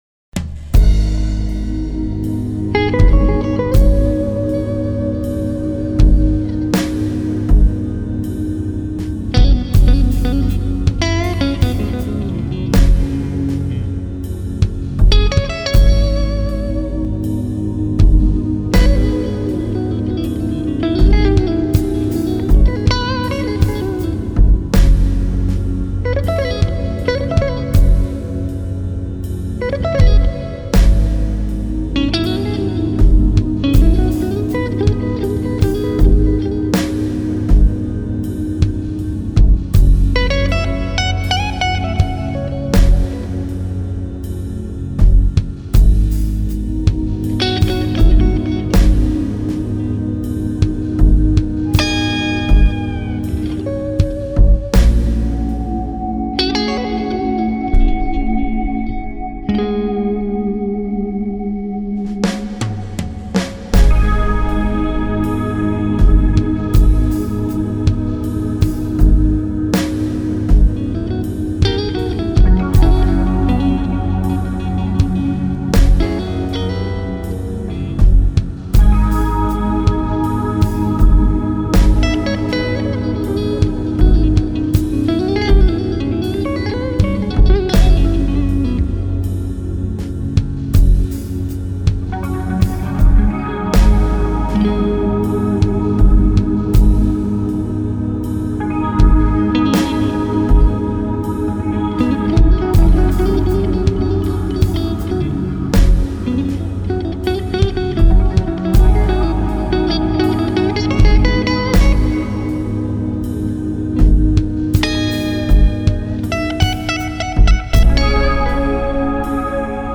Ich finde den Sound toll, absolut klassischer Blues-Sound von ganz früher mal.
Habe mir endlich eine Gibson SG (2016) mit zwei P-90 gekauft. Daher nahm ich heute wieder Just for Fun ein Blues Soli Beispiel per Handy auf. Der Sustain auch wenn mir ohne Backing Track sicher die Orientierung fehlte klingt selbst über Handy okay. your_browser_is_not_able_to_play_this_audio Ich denke es ist trotzdem nicht so mies eingespielt, auch wenn ich da natürlich dranbleiben sollte und etwas mehr üben sollte.